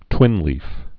(twĭnlēf)